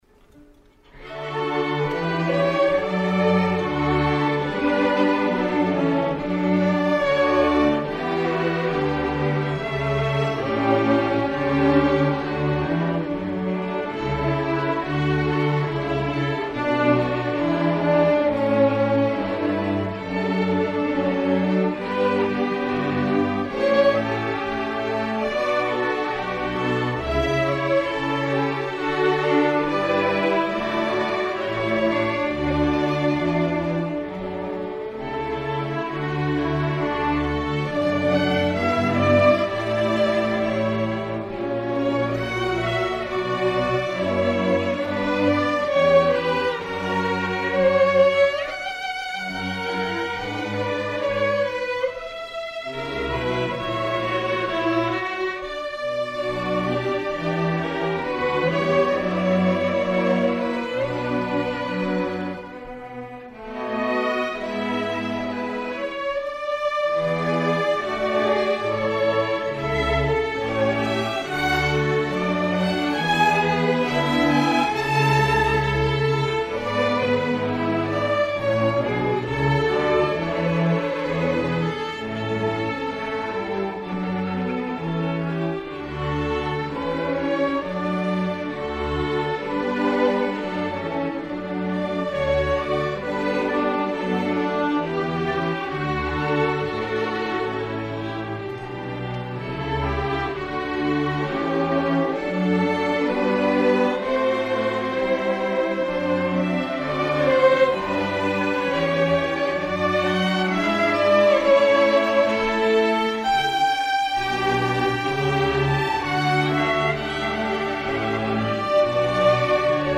Fall 2016 Concert